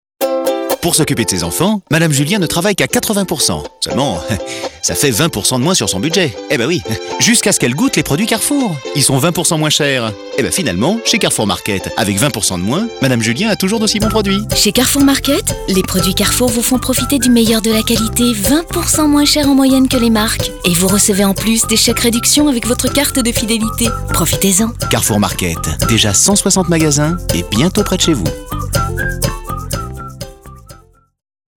Défi relevé avec cette campagne radio écrite pour Carrefour Market, avec la voix d’Emmanuel Curtil (la voix française de Jim Carrey) et des portraits de clients dans lesquels on sent toute la bienveillance de l’enseigne et son engagement à leur rendre service.